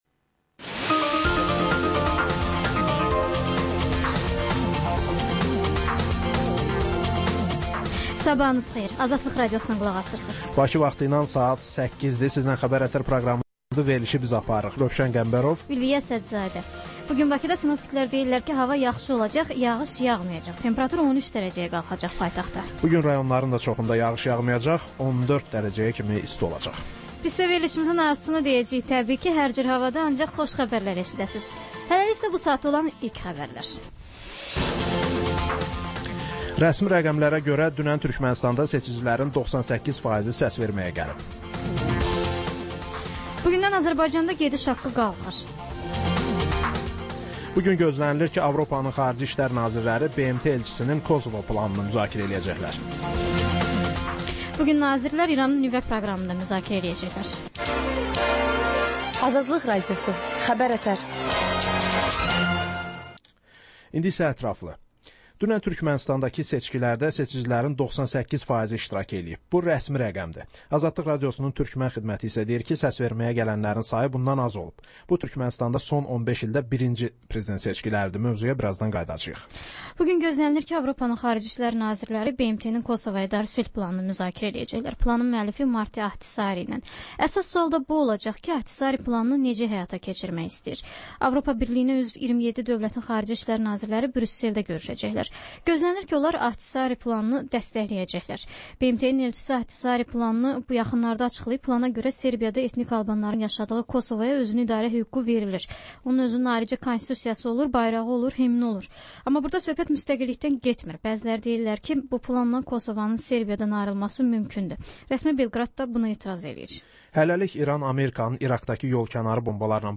Səhər-səhər, Xəbər-ətər: xəbərlər, reportajlar, müsahibələr.